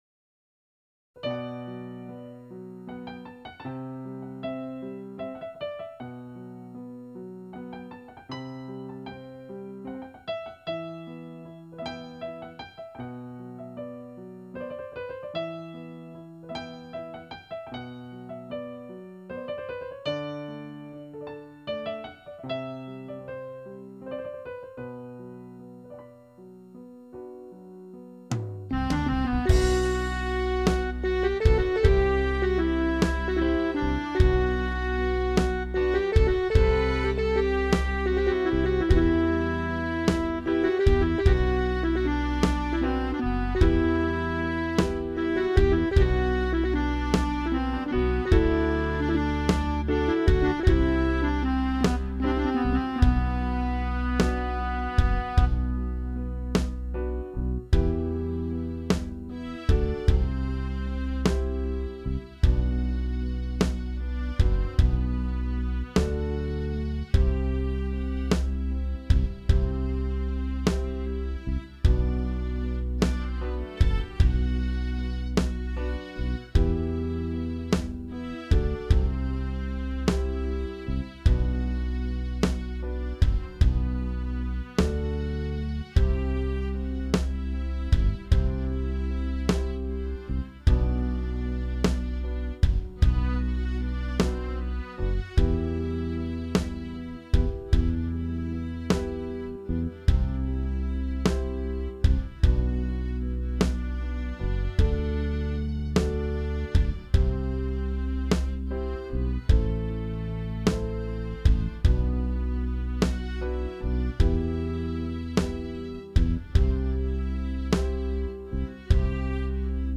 نسخه بیکلام